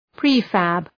{‘pri:fæb}